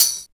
20 TAMB 2.wav